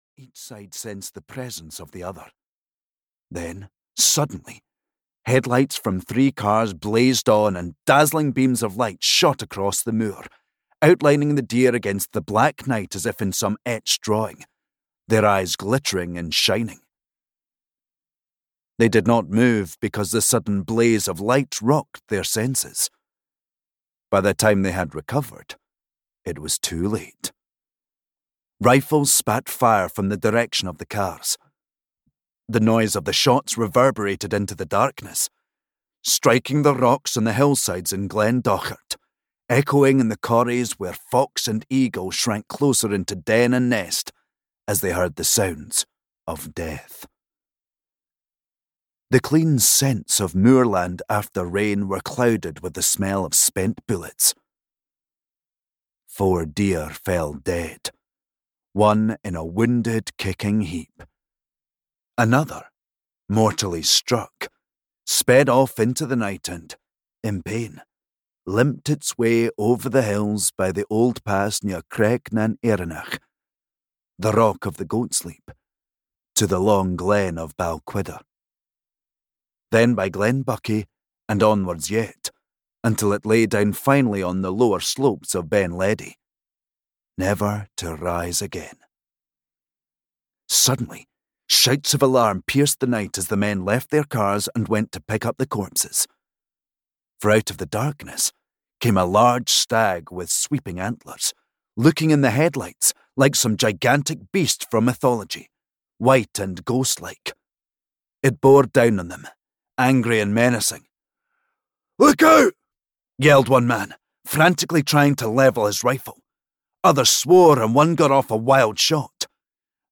The White Stag Adventure (EN) audiokniha
Ukázka z knihy